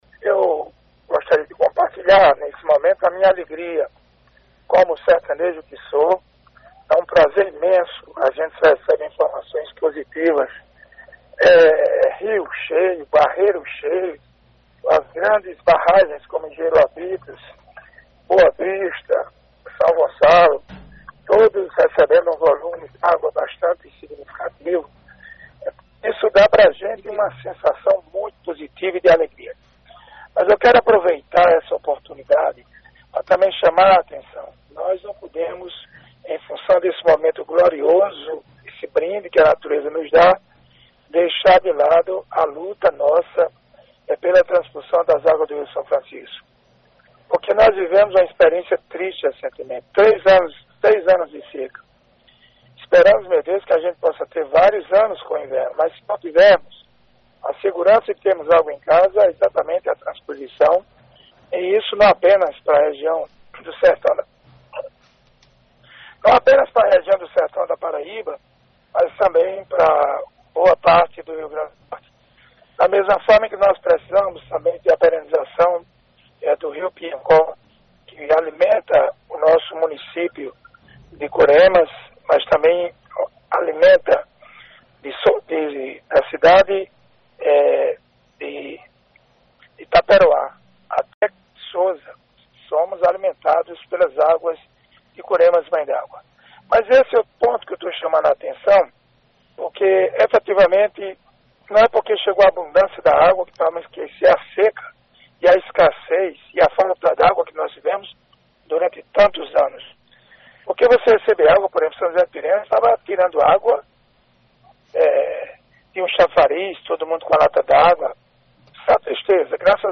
Durante participação ao vivo na imprensa de Cajazeiras nesta terça-feira(27) o deputado estadual Jeová Campos trouxe a tona um tema relevante e de interesse da comunidade: o retorno das chuvas e a necessidade do sertanejo conviver com a seca.
Ouça declaração do deputado Jeová Campos na Alto Piranhas AM